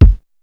DrKick12.wav